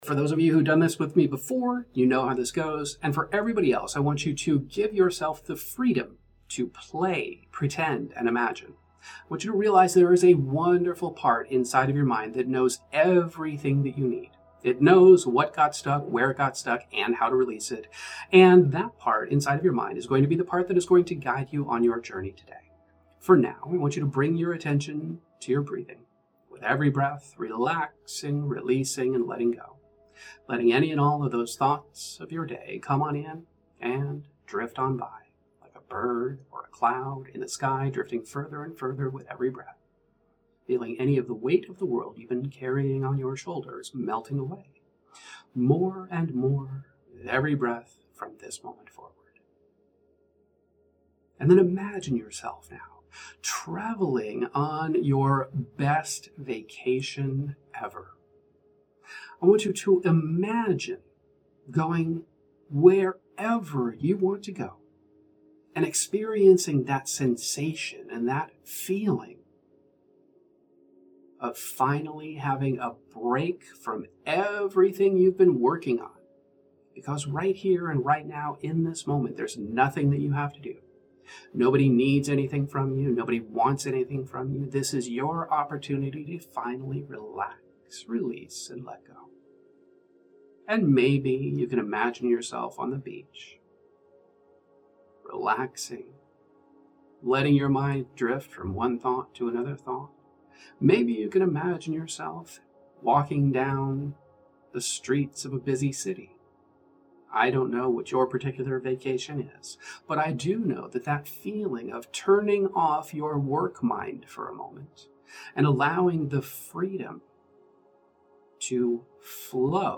8 Minute Mental Vacation Meditation (Time Bending) Guided Hypnotherapy Meditation